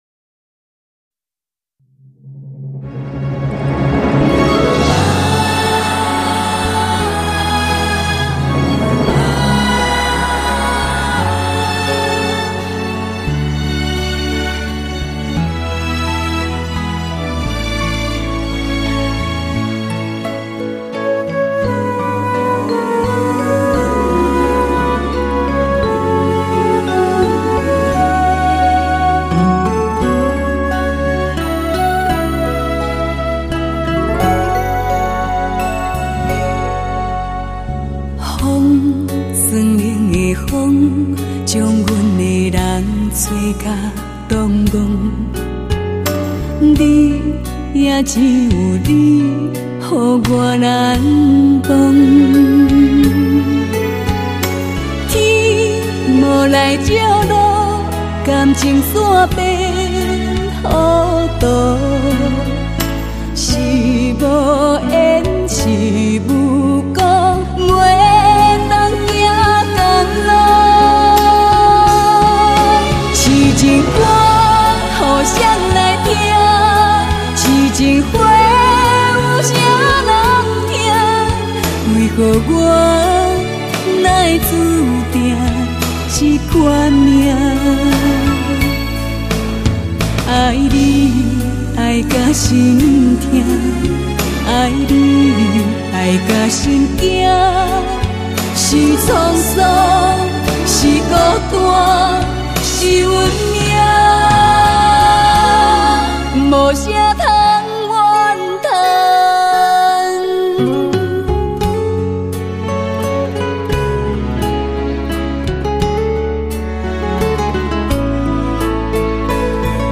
音乐风格: 流行
台语歌听得很少，这张专辑很好听，虽然听不懂，歌声揉揉甜甜的，旋律流畅优美。